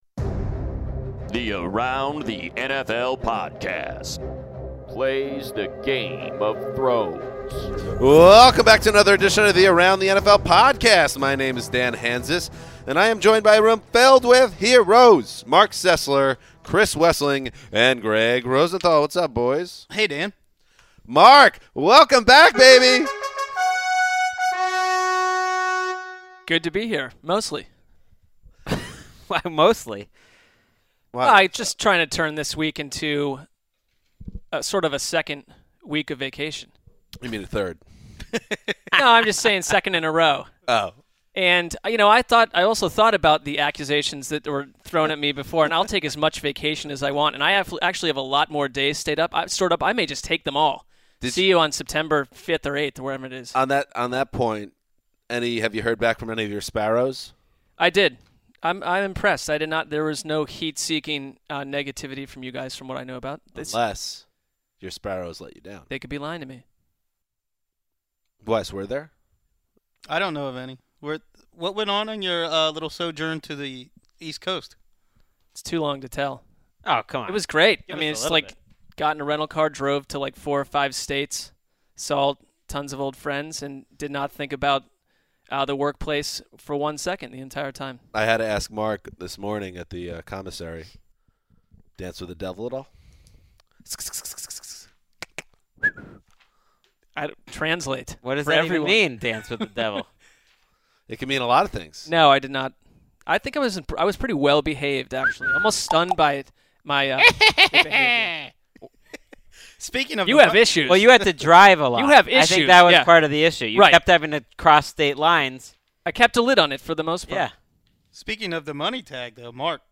Finally, Arizona Cardinals cornerback Patrick Peterson is in studio to let us know who is more valuable to the defense, Tyrann Mathieu or Patrick Peterson?